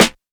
Dilla Snare 03.wav